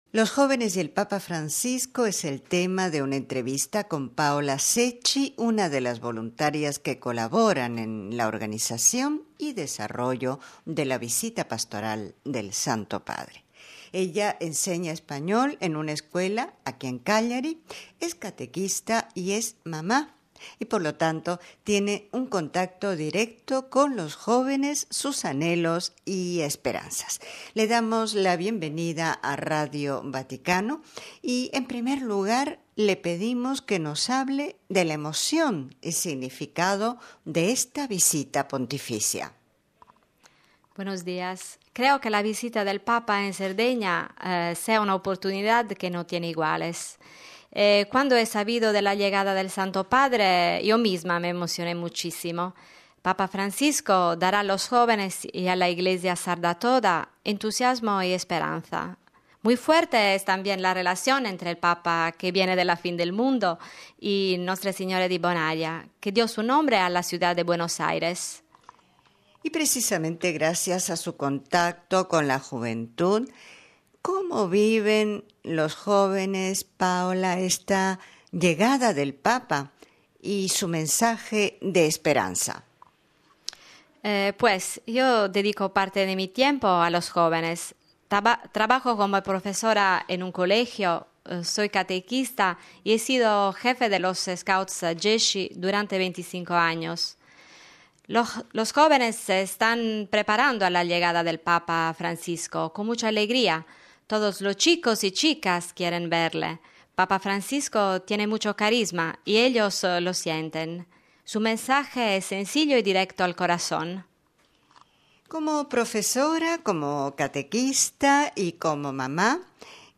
Es el tema de una entrevista